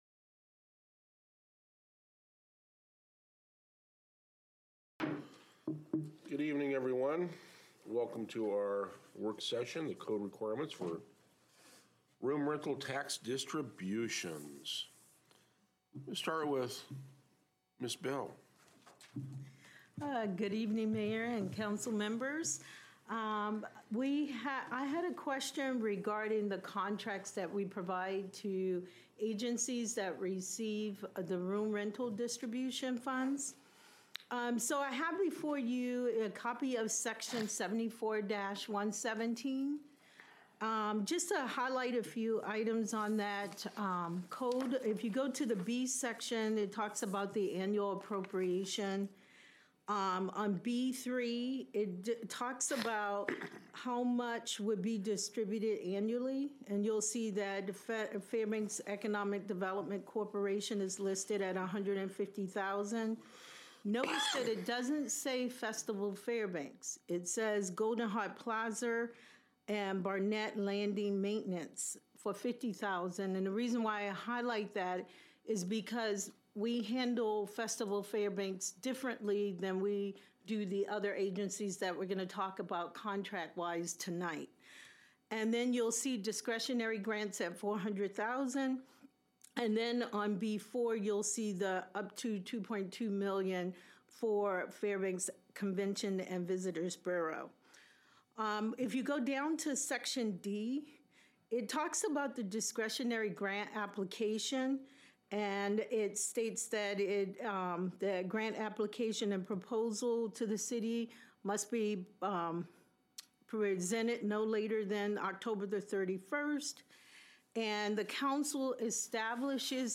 Special Council Work Session on Code Requirements for Room Rental Tax Distributions
Patrick B Cole City Hall 800 Cushman Street Fairbanks, AR 99701